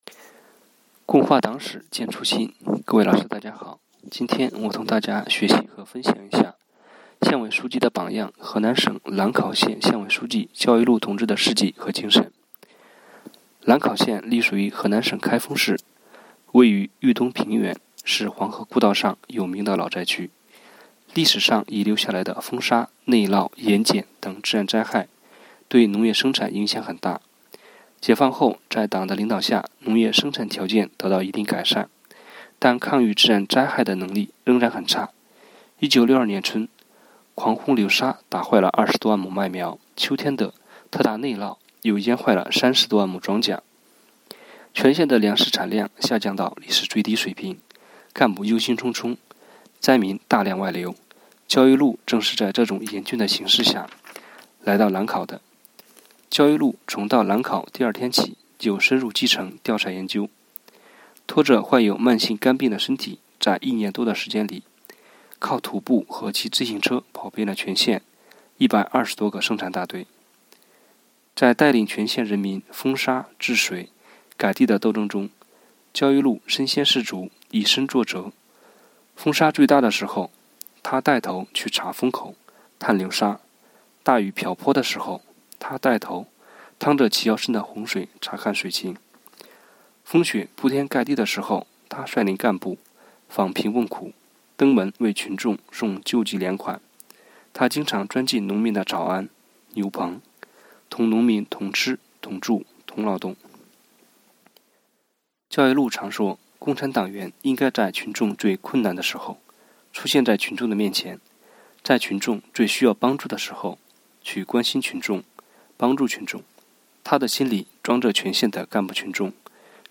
序章：为了加强组织建设，提高党员教育成效，落实党员“五个一”活动要求，科技处党支部从3月23日起开展了“共话党史践初心——党史人人讲”系列活动，党员每天讲一个党史故事，辅以相关学习内容的延伸阅读，以小见大，不断巩固“不忘初心 牢记使命”主题教育成果，以昂扬的精神面貌，庆祝中国共产党成立100周年！！！